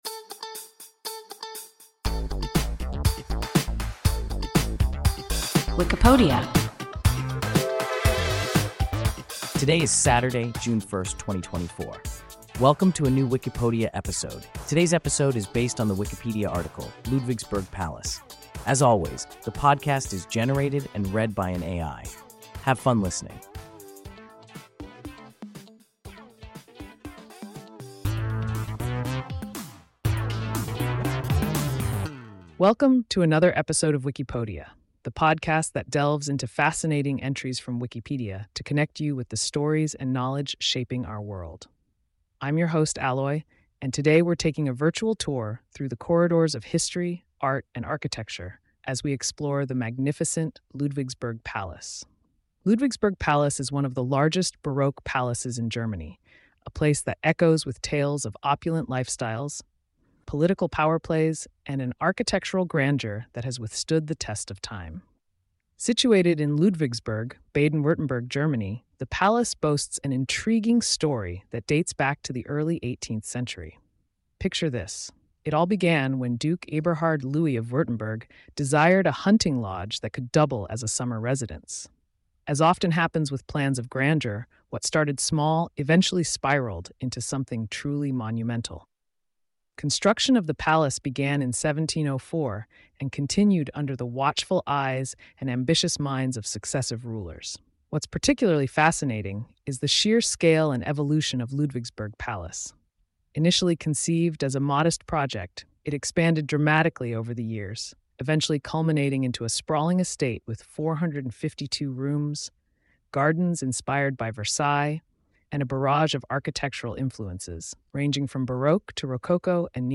Ludwigsburg Palace – WIKIPODIA – ein KI Podcast